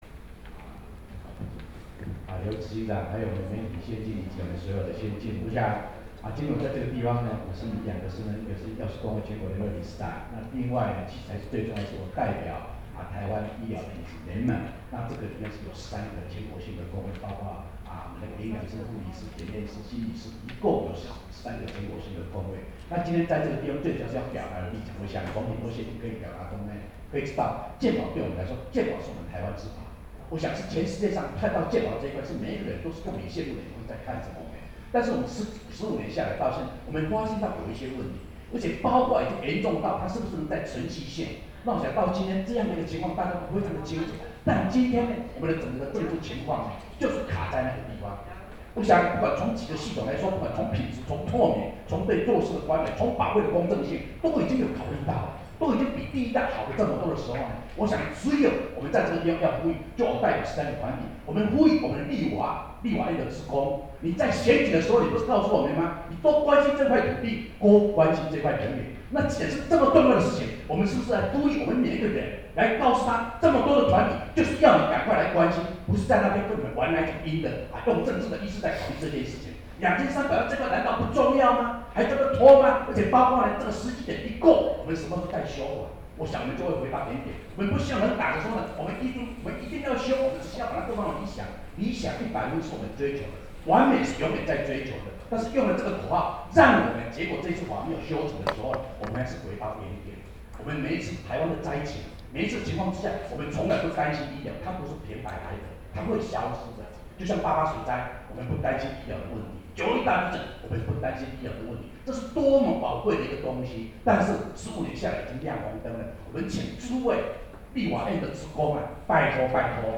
「健保改革不能退怯  修法時機必須掌握」記者會
發言錄音檔